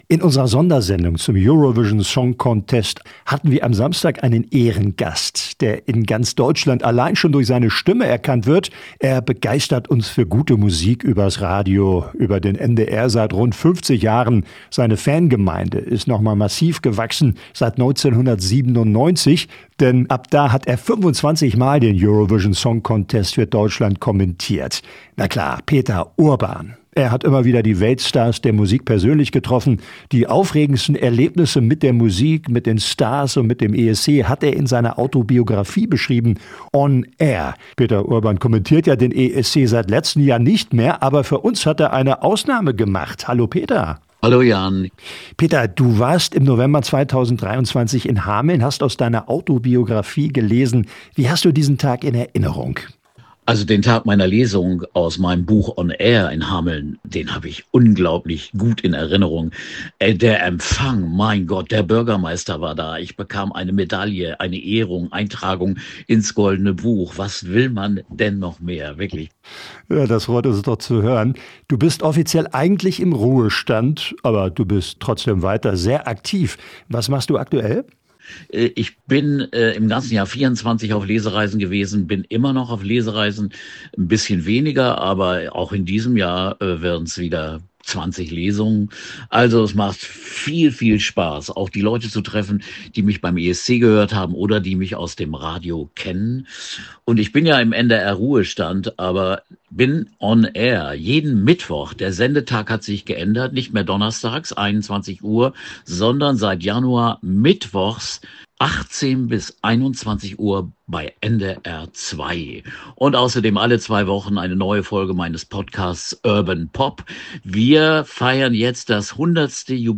Der legendäre ESC-Kommentator und NDR 2-Moderator Peter Urban im Gespräch bei radio aktiv – radio aktiv
der-legendaere-esc-kommentator-und-ndr2-moderator-peter-urban-im-gespraech-bei-radio-aktiv.mp3